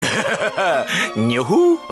gyro zeppeli Meme Sound Effect
Category: Anime Soundboard